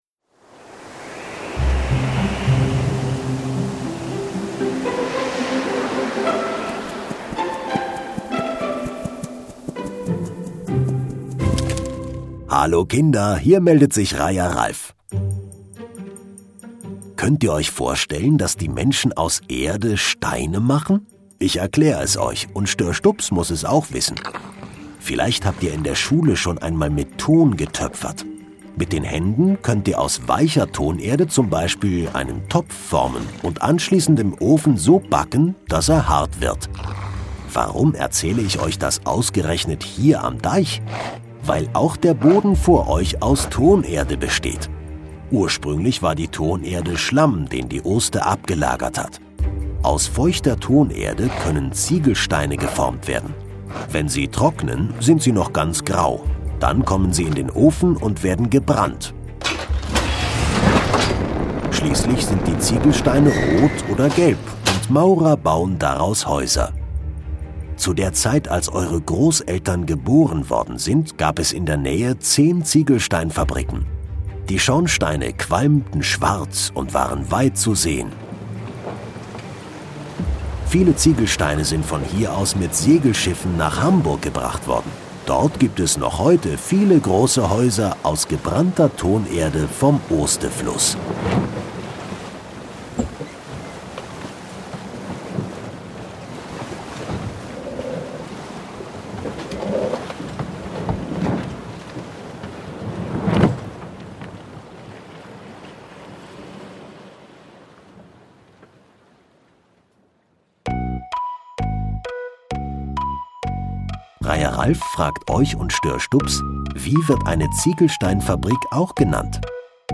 Historische Ziegelei - Kinder-Audio-Guide Oste-Natur-Navi